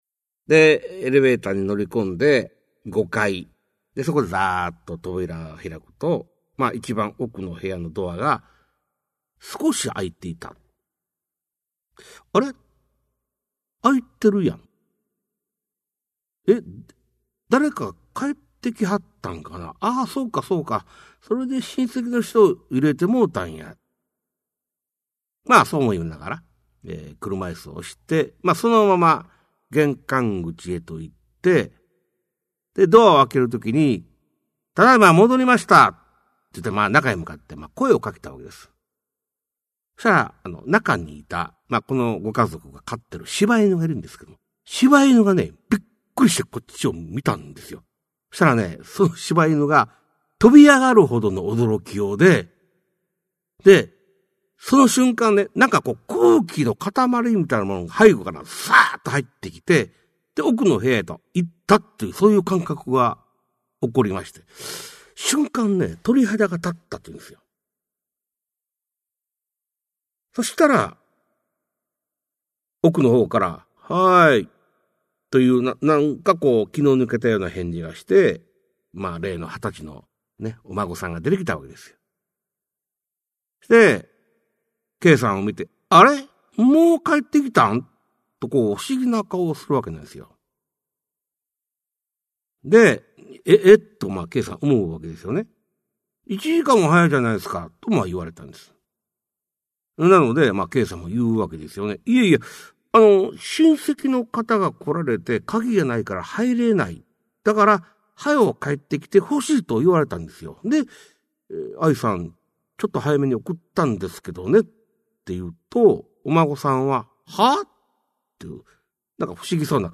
[オーディオブック] 市朗怪全集 四十八
実話系怪談のパイオニア、『新耳袋』シリーズの著者の一人が、語りで送る怪談全集! 1990年代に巻き起こったJホラー・ブームを牽引した実話怪談界の大御所が、満を持して登場する!!